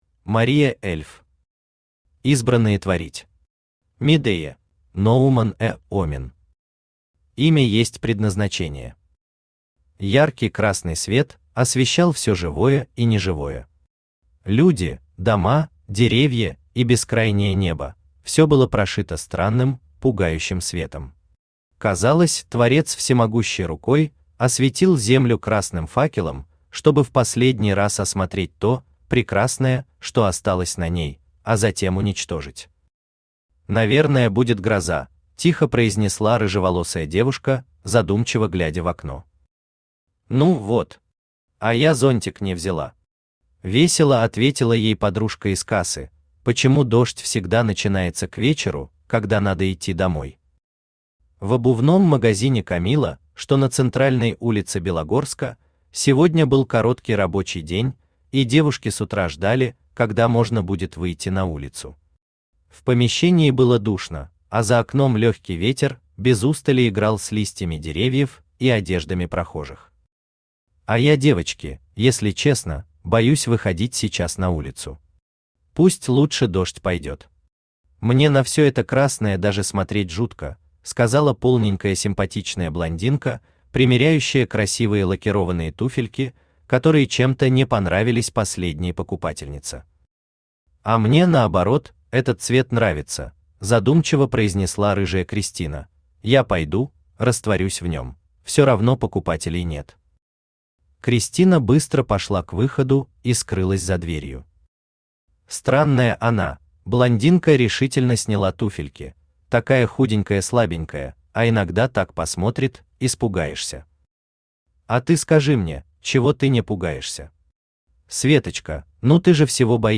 Аудиокнига Избранные творить. Медея | Библиотека аудиокниг
Медея Автор Мария Эльф Читает аудиокнигу Авточтец ЛитРес.